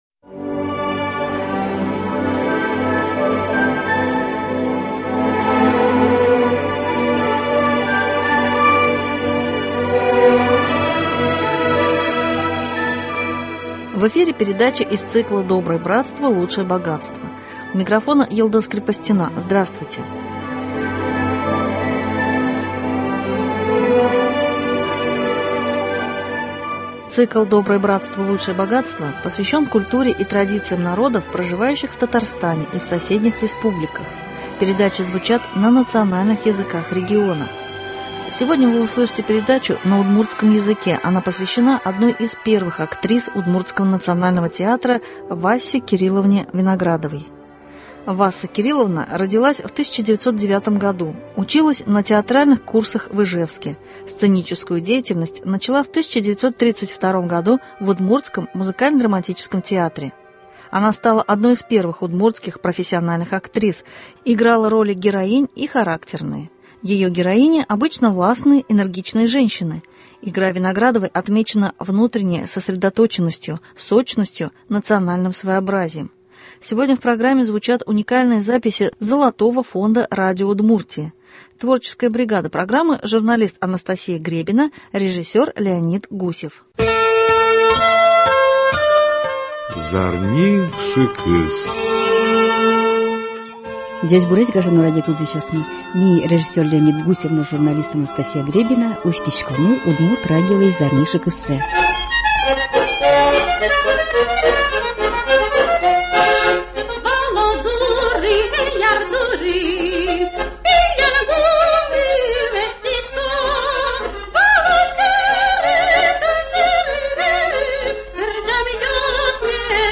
Сегодня в программе звучат уникальные записи Золотого фонда радио Удмуртии.